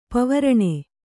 ♪ pavaraṇe